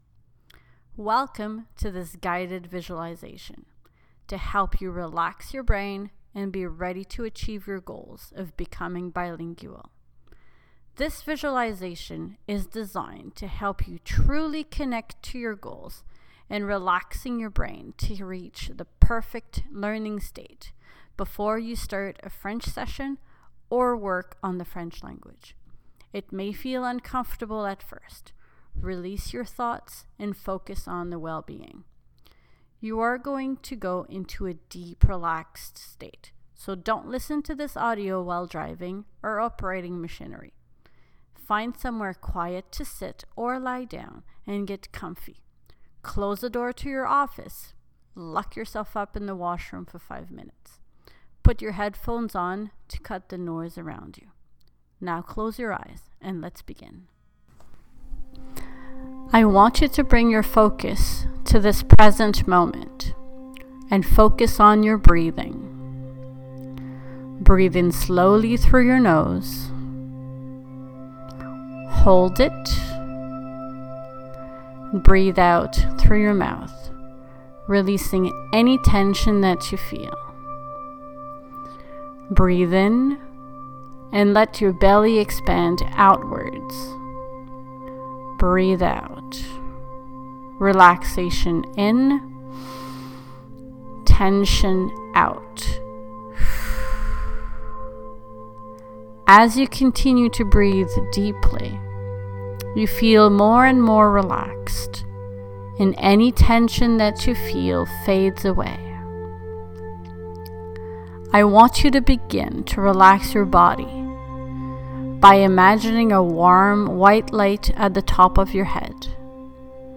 Start experimenting with this quick 5-minute guided visualization to help your brain fully relax before you jump into your next French exercise.